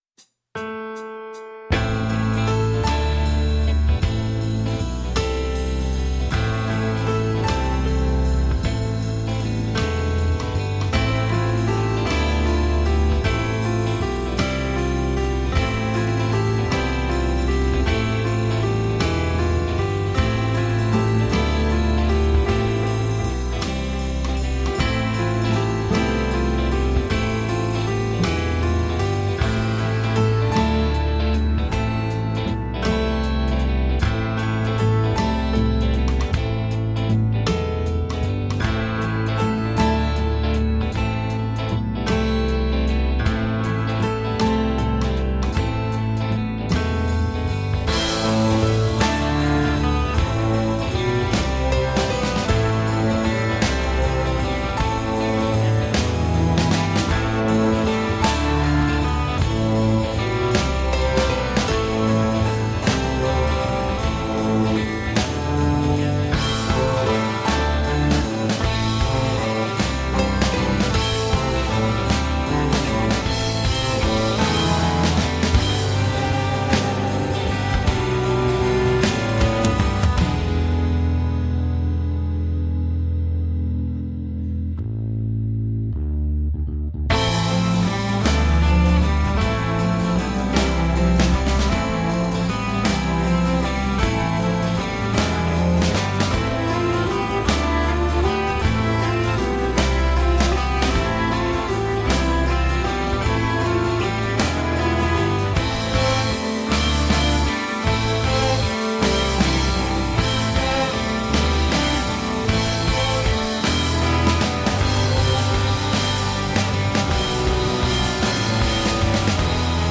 Tagged as: Alt Rock, Rock